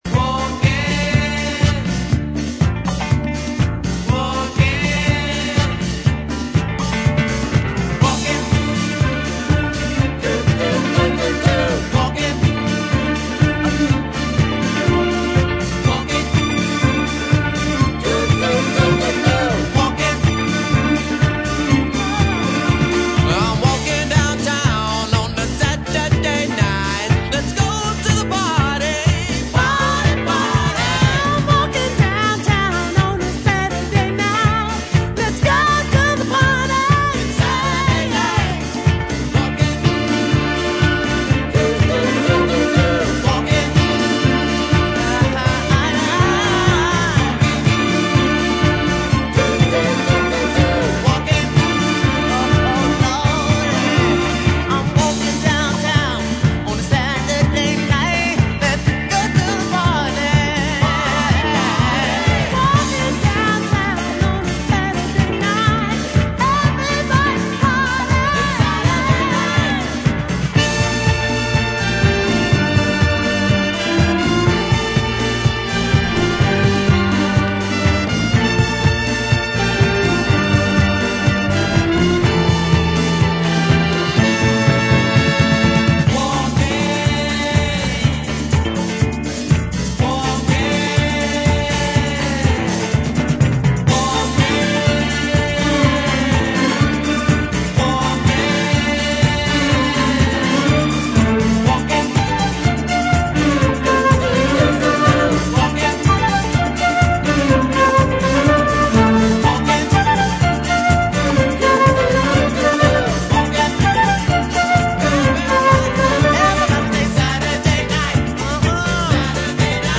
ジャンル(スタイル) DISCO / DANCE CLASSIC